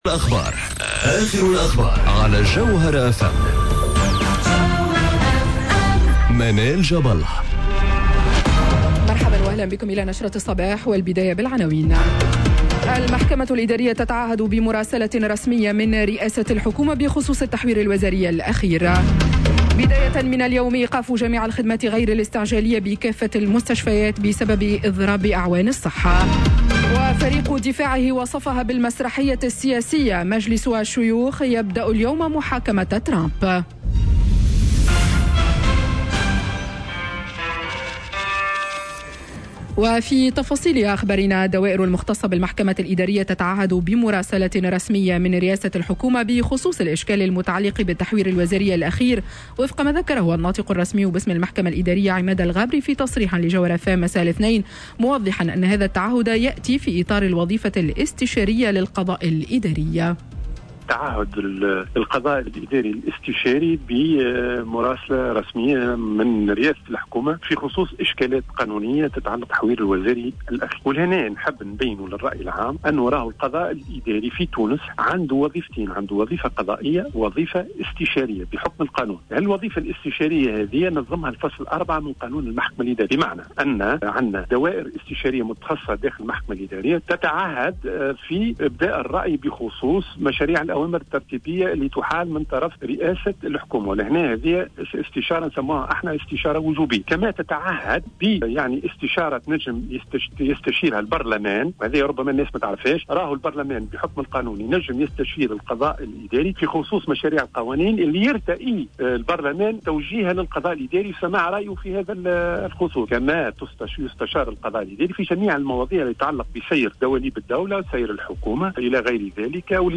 نشرة أخبار السابعة صباحا ليوم الثلاثاء 09 فيفري 2021